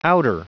Prononciation du mot outer en anglais (fichier audio)
Prononciation du mot : outer